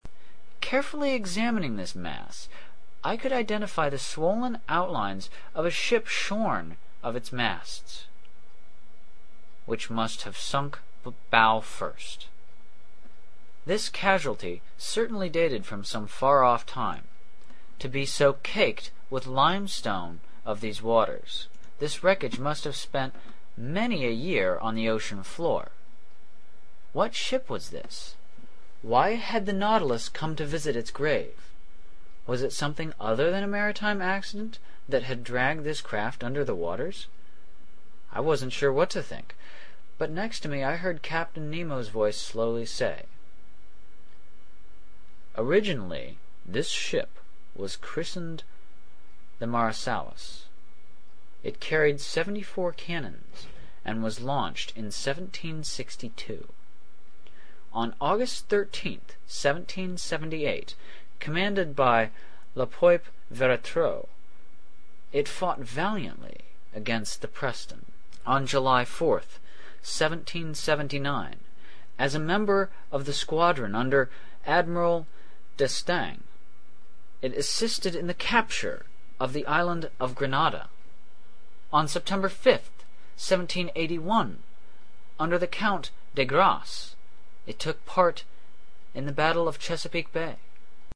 英语听书《海底两万里》第538期 第33章 北纬47.24度, 西经17.28度(13) 听力文件下载—在线英语听力室